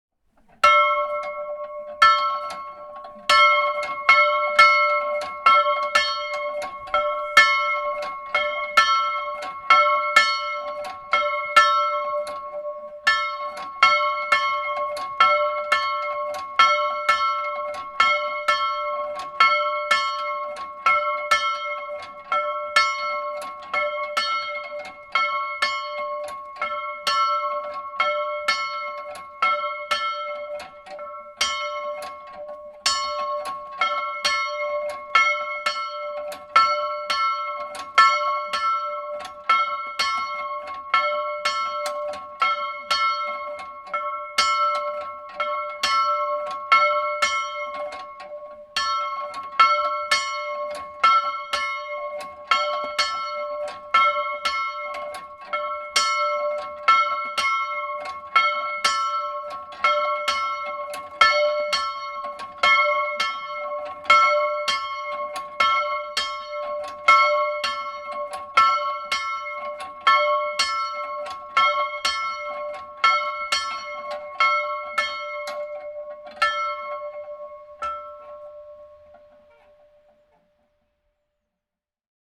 Glockengeläut
Man braucht einen guten Impuls, wenn man am Seil zieht, muss der Glocke dann aber den Raum zum Zurückschwingen geben. Sonst lässt die Glocke immer wieder einen Schlag aus.
glockengelaeut-data.mp3